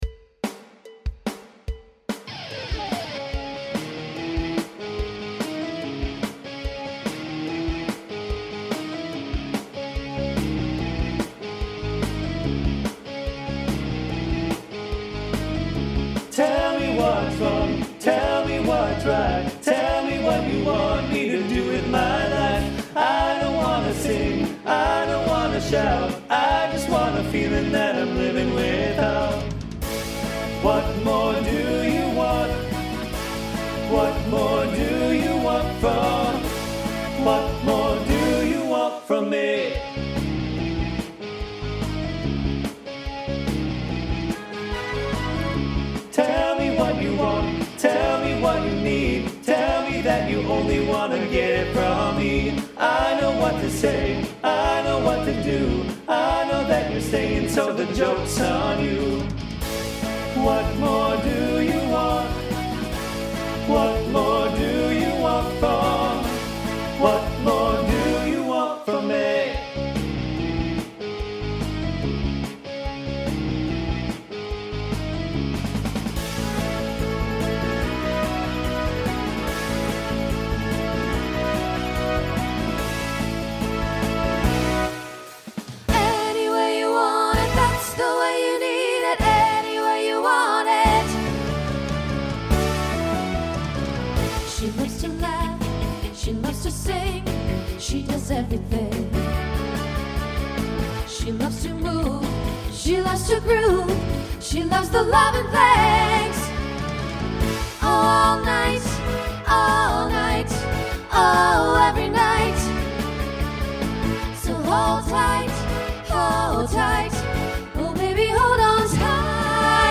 TTB/SSA
Voicing Mixed Instrumental combo Genre Rock